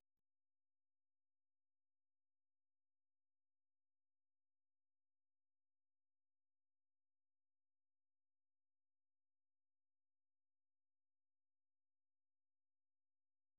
Форма струнный квартет[d]
Тональность до-диез минор[1]
Adagio ma non troppo e molto espressivo. Написано в виде фуги.